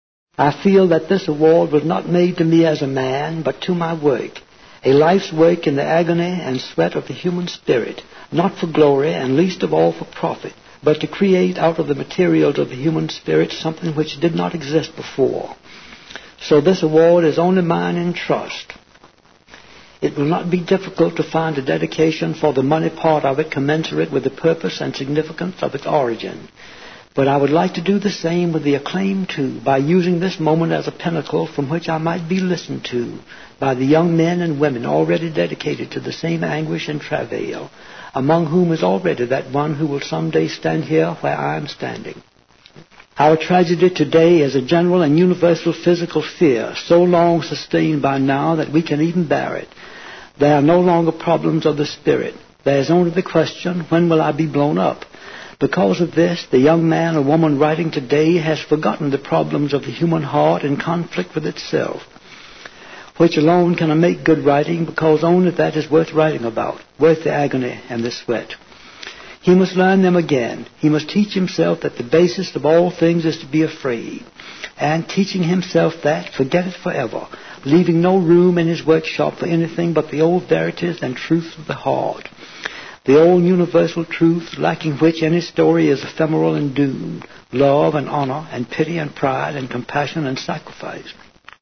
名人励志英语演讲 第115期:人类的精神(1) 听力文件下载—在线英语听力室